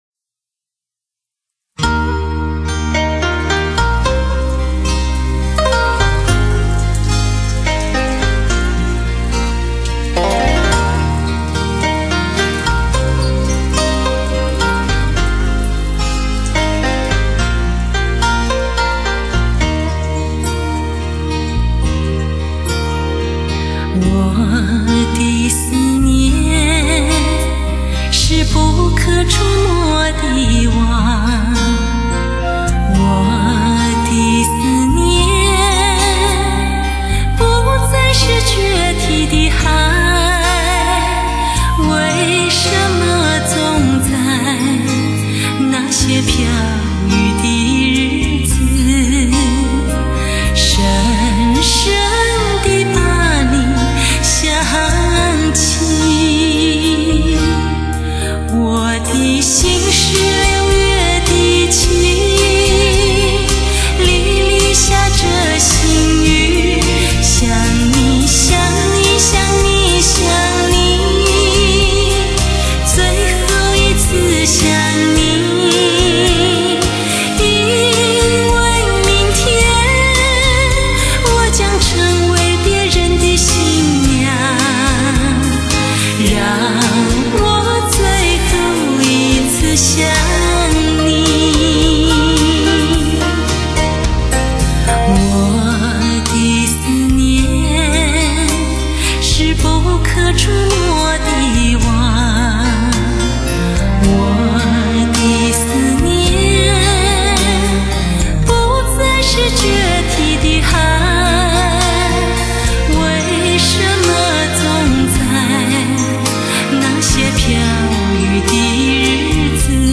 传奇真空管处理 顶级音效极品典藏
發燒極品，百聽不厭;全頻段六聲道製作如同置身音樂聽之中。傳奇真空管處理，頂級音效環繞體验！